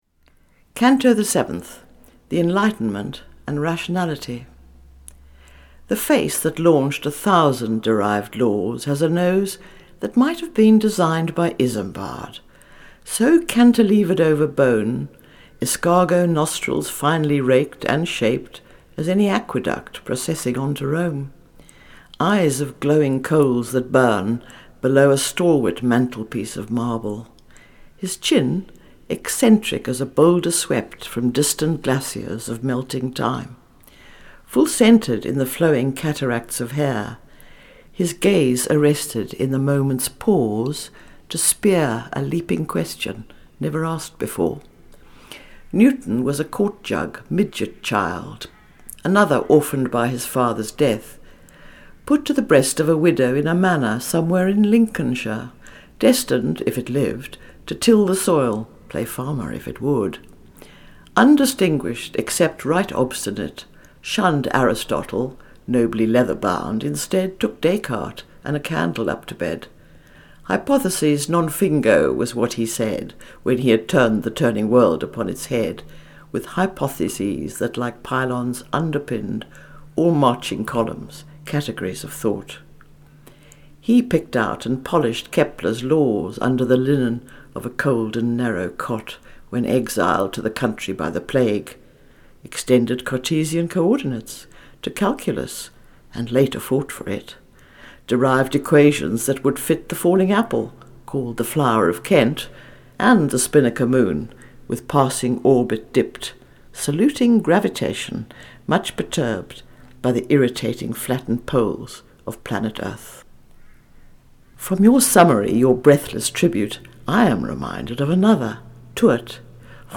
Three Readings from Involution.